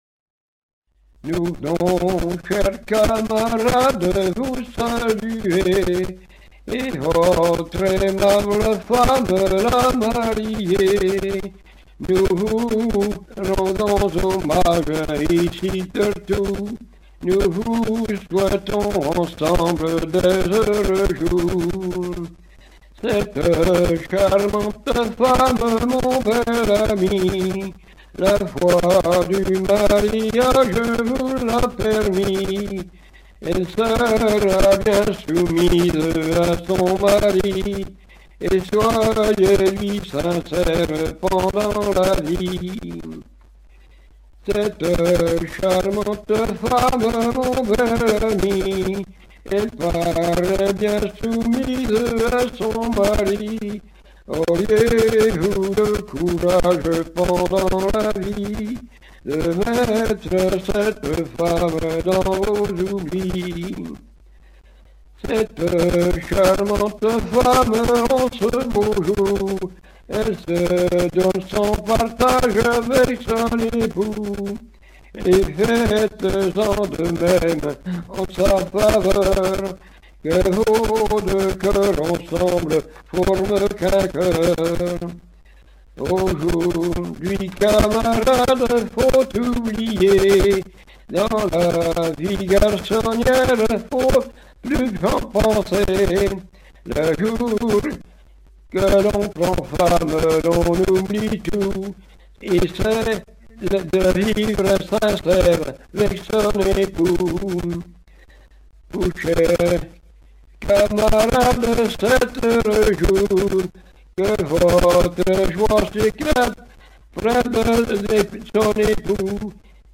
circonstance : fiançaille, noce
Genre strophique